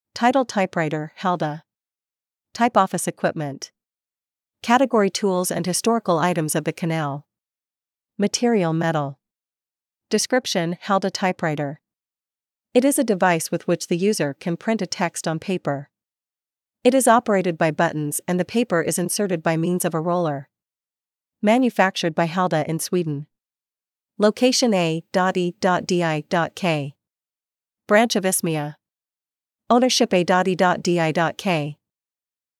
Γραφομηχανή HALDA